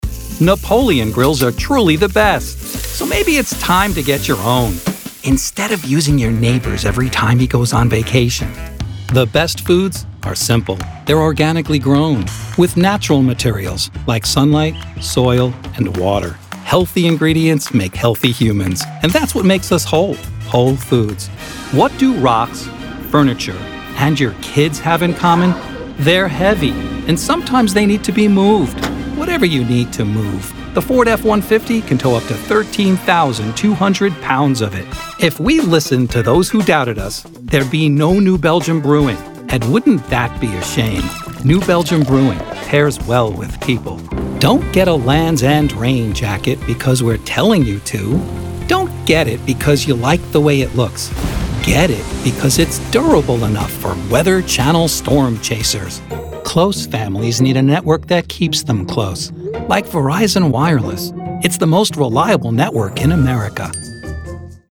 A commercial demo featuring six business samples
New York "Brooklynese"
Middle Aged